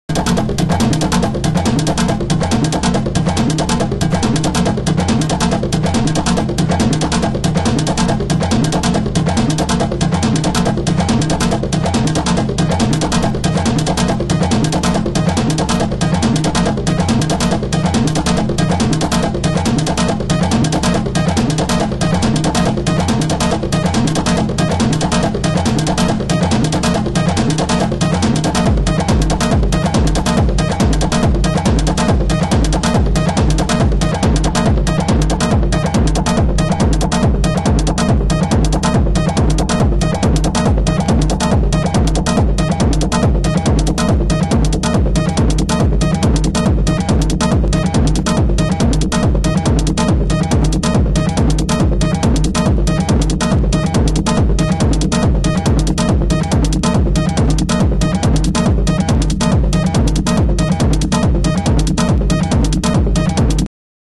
盤質：A1の 終盤、A2 の 残半に傷有/小傷、盤面汚れによる少しチリパチノイズ有/ラベルにシール跡有